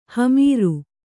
♪ hamīru